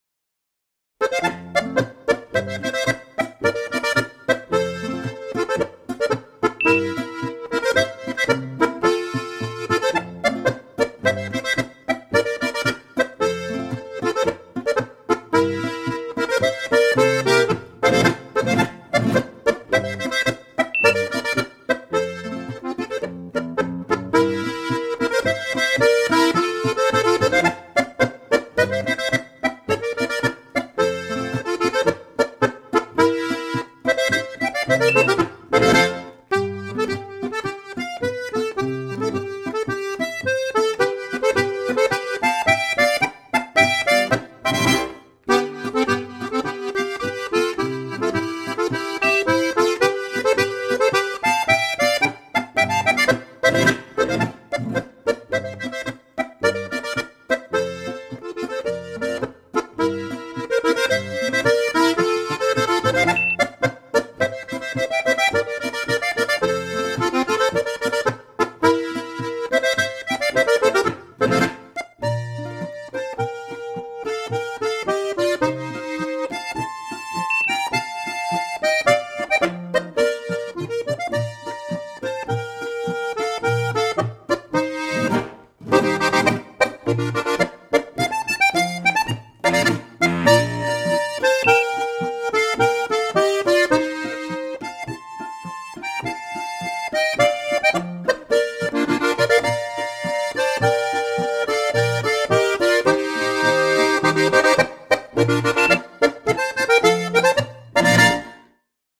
4-reihige steirische Harmonika
Polka Francé